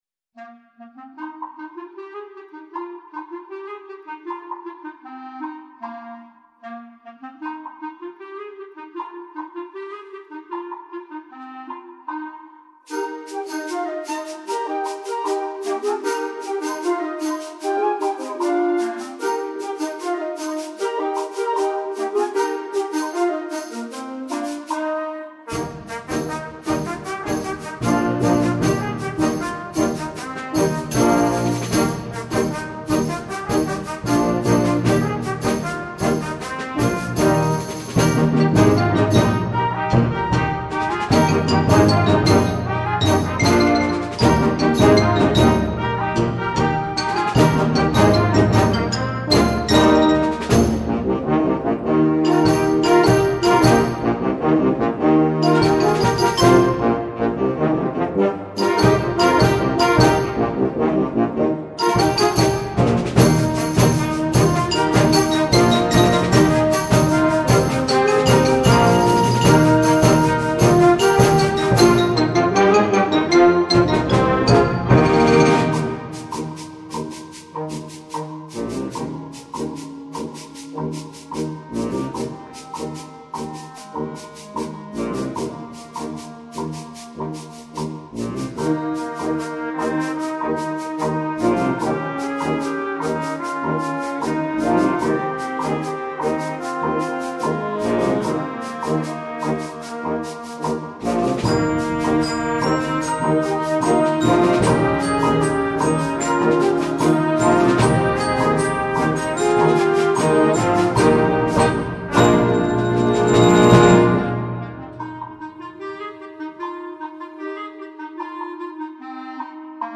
Concert Band
energetic, uplifting piece